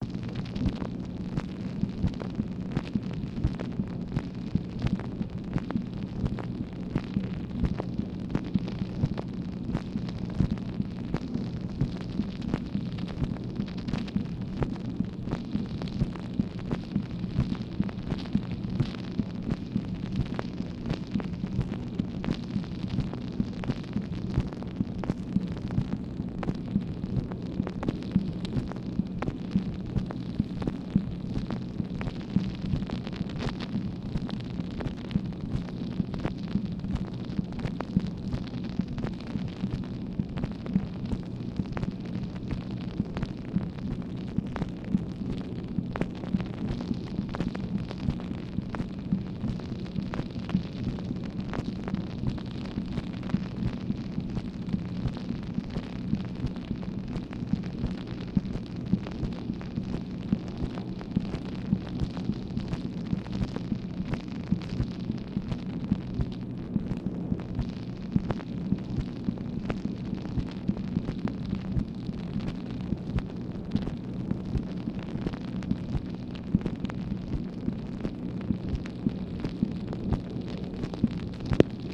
MACHINE NOISE, June 28, 1965
Secret White House Tapes | Lyndon B. Johnson Presidency